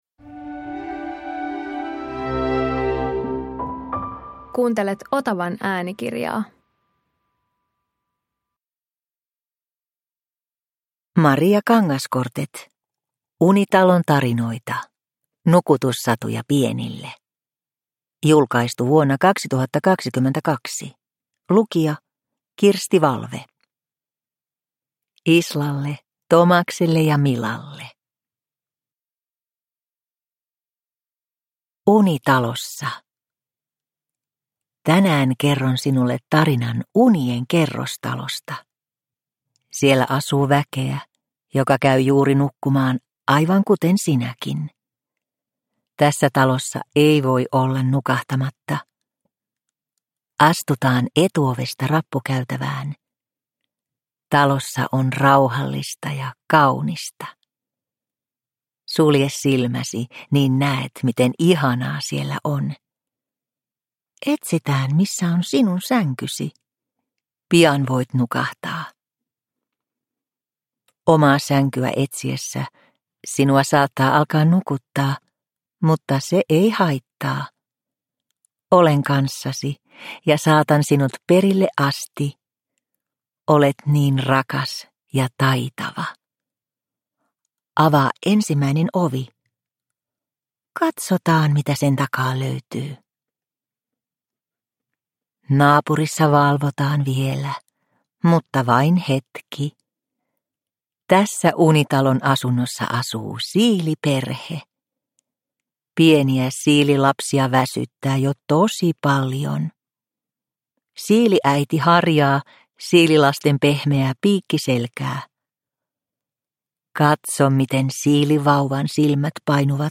Unitalon tarinoita – Ljudbok – Laddas ner